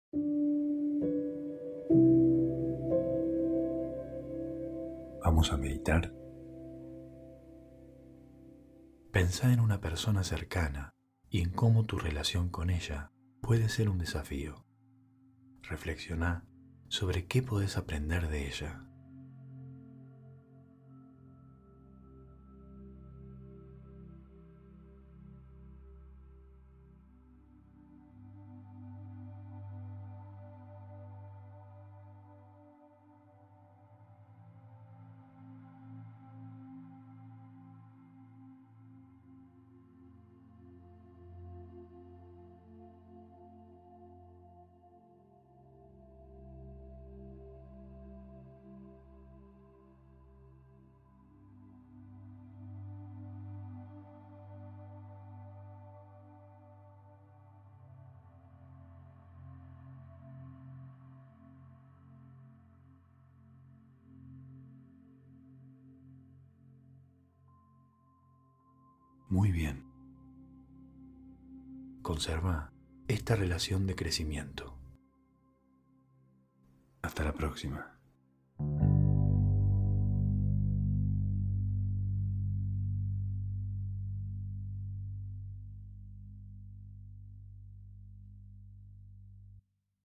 Meditación de 1 minuto para reflexionar.